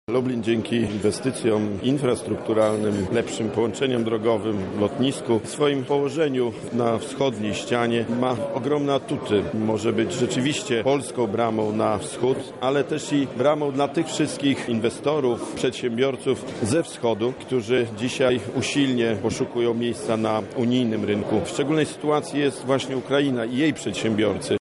Rozpoczęło się Wschodnie Forum Gospodarcze LUB INVEST 2015.
W naszym interesie leży tworzenie miejsc pracy po ukraińskiej stronie – mówi były Minister Gospodarki, Jacek Piechota.